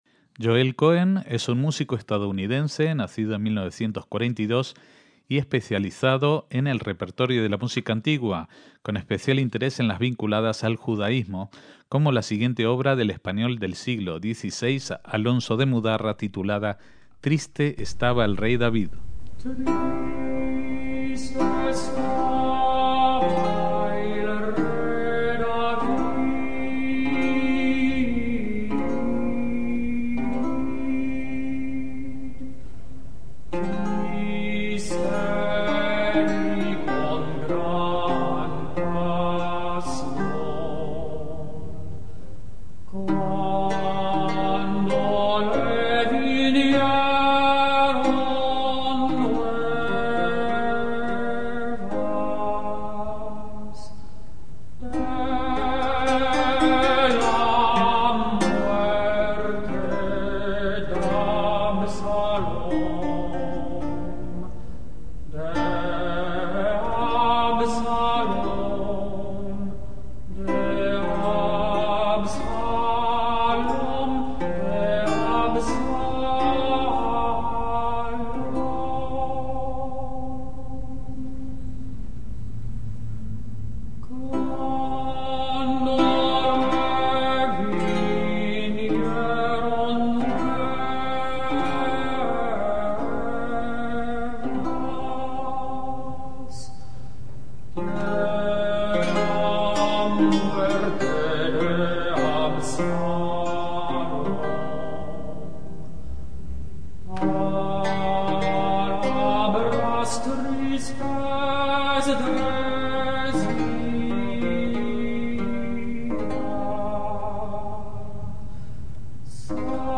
MÚSICA CLÁSICA - Joel Cohen es un músico estadounidense, nacido en 1942, especializado en repertorios de música antigua, habiéndose formado en las universidades de Brown y Harvard.